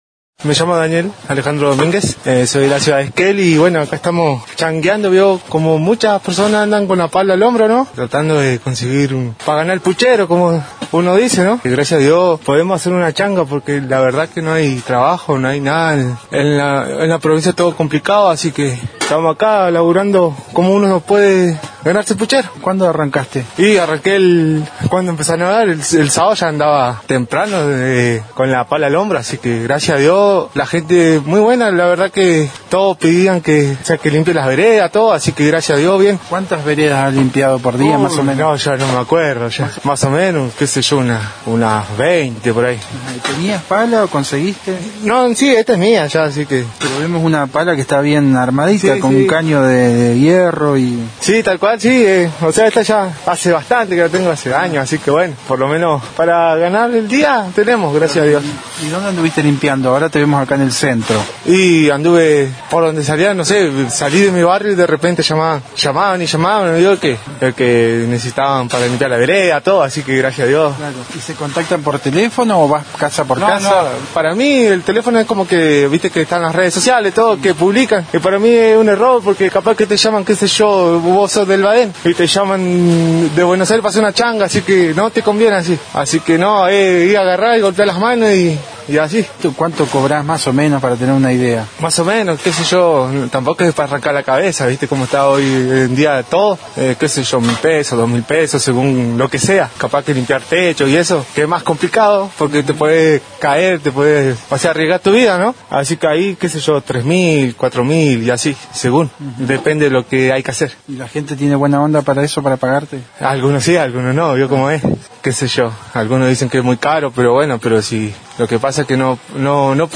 conversó con Noticias de Esquel y relato que anda “changueando” para poder “ganarse el puchero”.